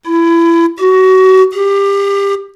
Sonagramme de flûte de Pan